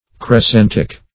Search Result for " crescentic" : The Collaborative International Dictionary of English v.0.48: Crescentic \Cres*cen"tic\ (kr[e^]s*s[e^]n"t[i^]k), a. Crescent-shaped.